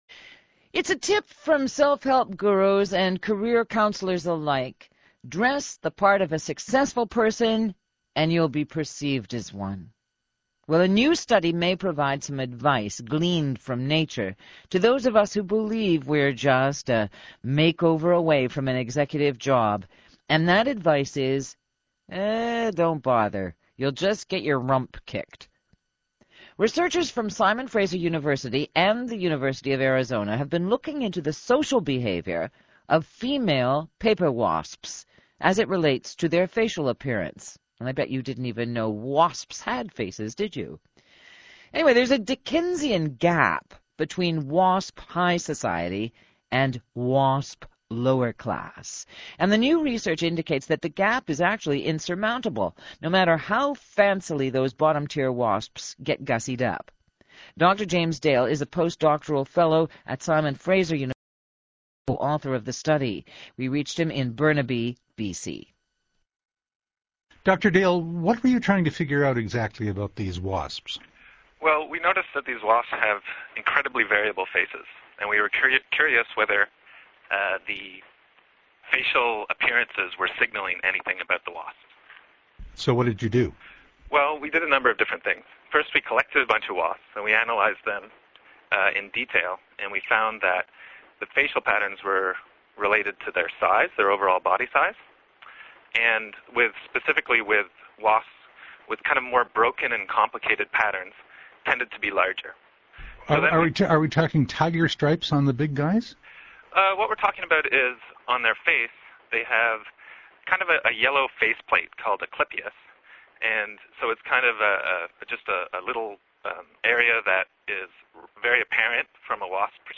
CBC radio (mp3)    Faculty of 1000    More about wasps